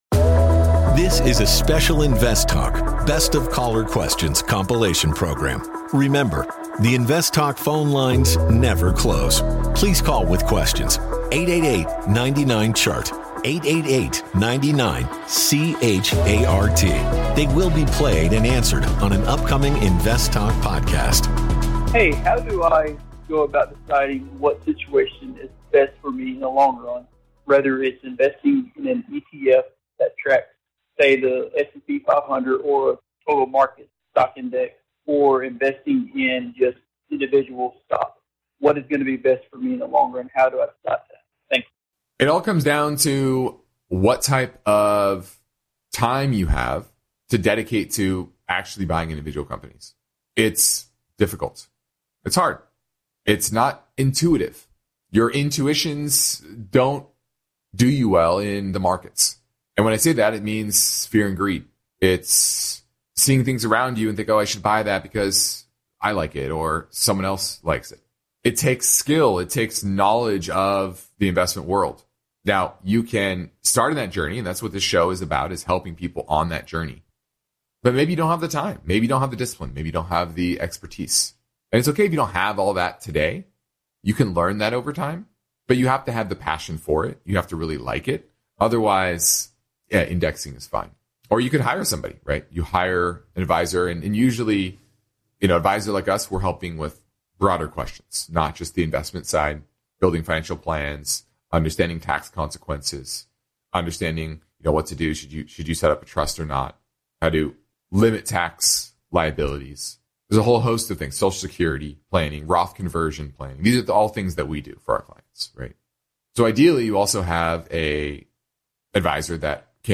Juneteenth - Best of Caller Questions